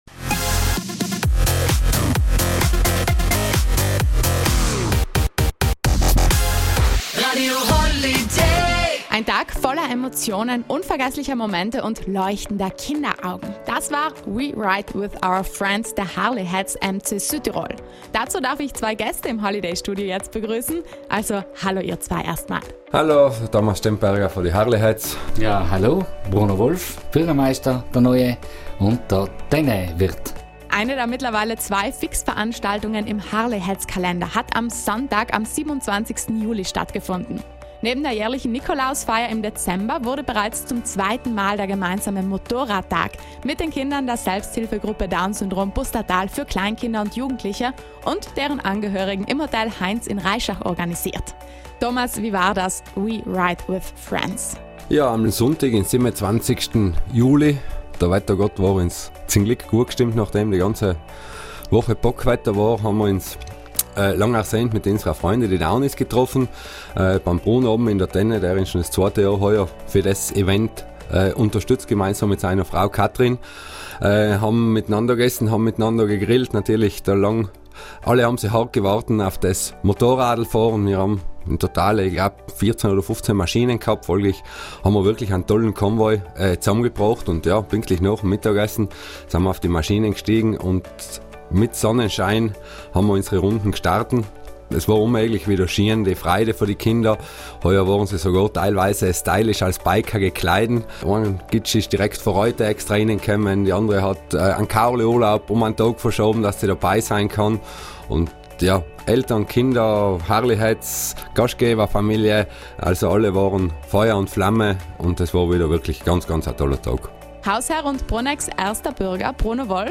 Die Harley Heads erzählen auf Radio Holiday vom Hammertag:
Radio-Holyday-Interview-mit-Harley-Heads.mp3